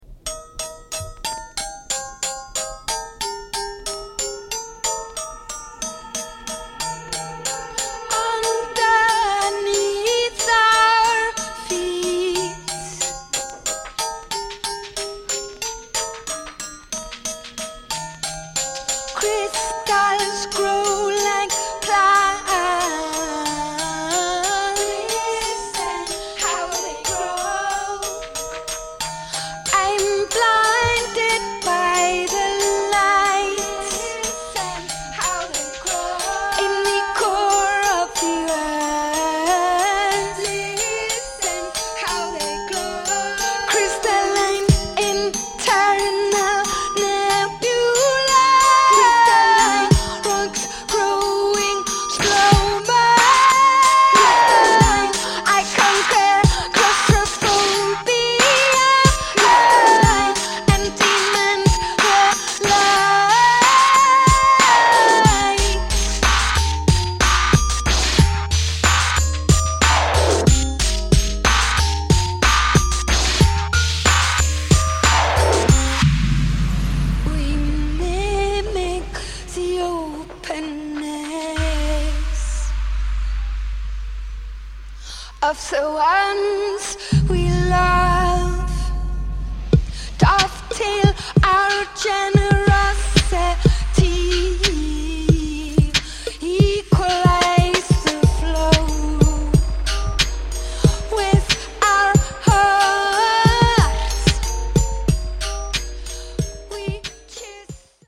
Electronica. Leftfield